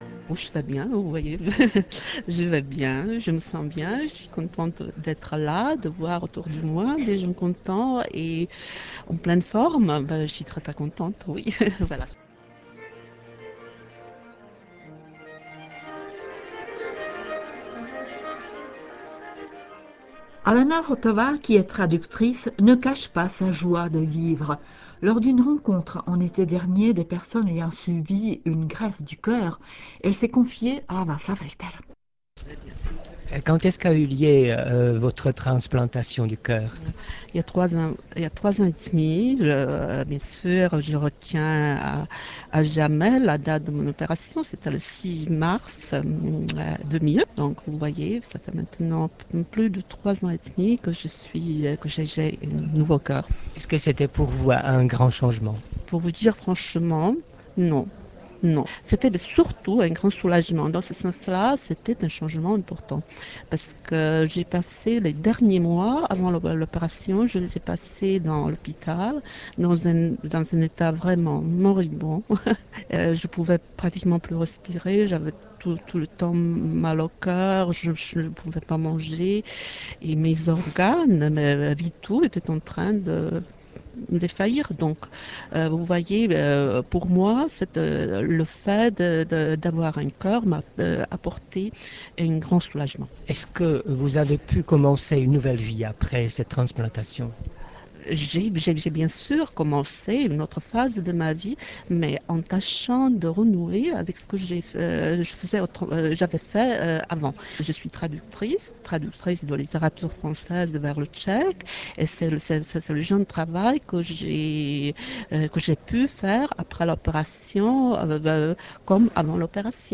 J'espère que le choix des extraits de quelques interviews intéressantes que mes collègues ont réalisées au cours de cette année fera plaisir à vous tous qui êtes actuellement à l'écoute.